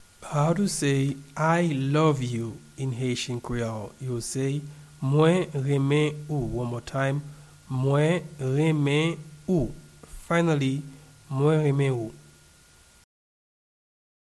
Pronunciation and Transcript:
I-love-you-in-Haitian-Creole-Mwen-renmen-ou-pronunciation.mp3